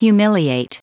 Prononciation du mot humiliate en anglais (fichier audio)
Prononciation du mot : humiliate